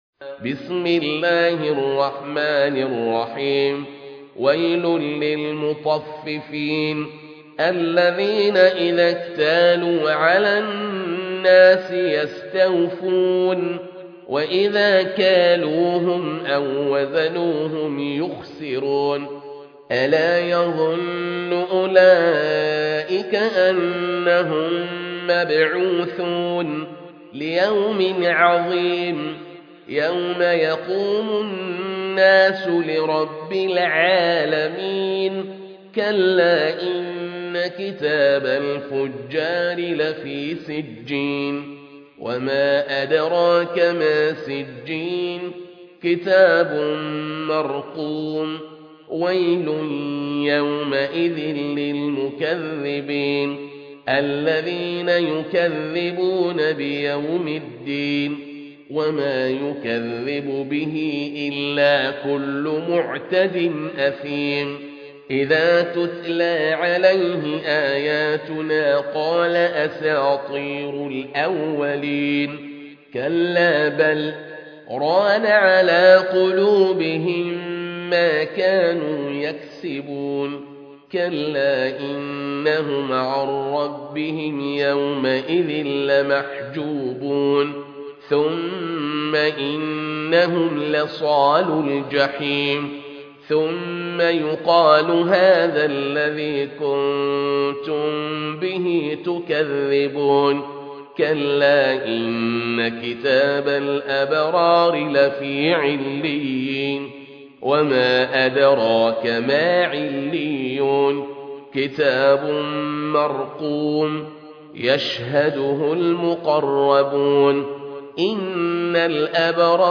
المصحف المرتل - حفص عن عاصم